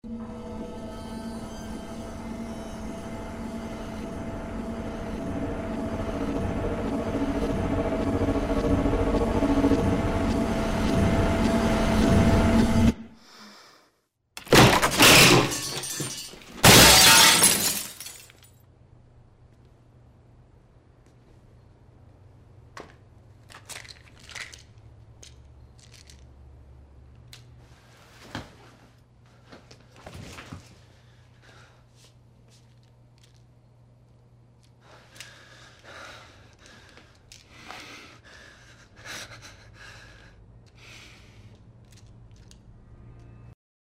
Absolument aucun changement, les bandes sons sont extraites de la vidéo en VO.
Réponse : House est chez lui, dans sa salle de bain, il arrache un miroir et je jette dans la baignoire afin d'attraper les 2 boites de vicodin cachées derrière. (6x22 Help Me)